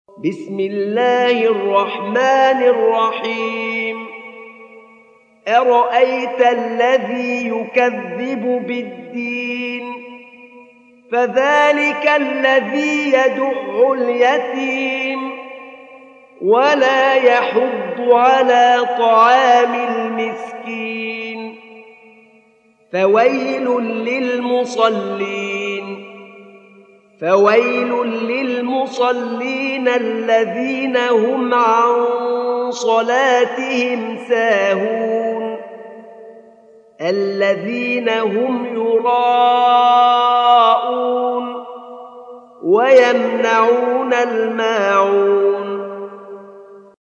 سورة الماعون | القارئ أحمد نعينع